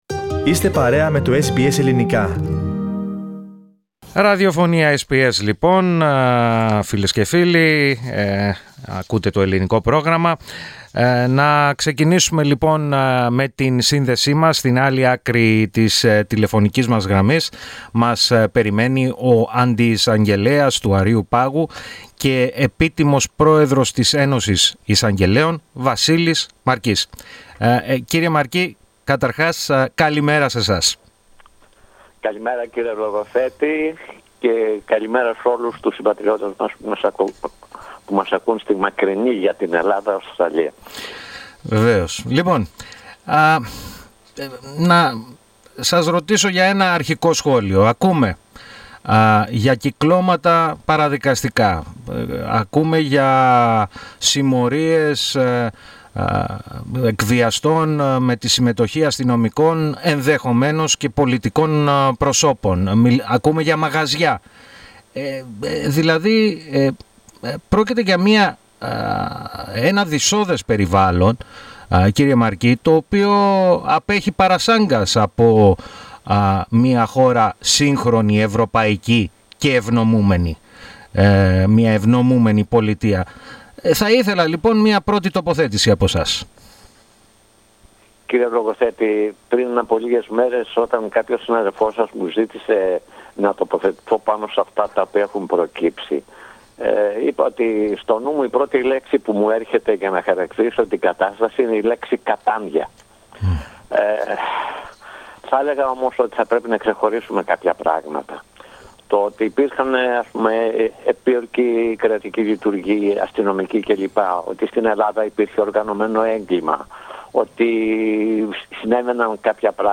Με αφορμή τις καταγγελίες για παραδικαστικά και παρακρατικά κυκλώματα στην Ελλάδα, μίλησε στο Ελληνικό Πρόγραμμα της ραδιοφωνίας SBS, ο πρώην Αντιεισαγγελέας του Αρείου Πάγου, και Επίτιμος Πρόεδρος της Ένωσης Εισαγγελέων, Βασίλης Μαρκής.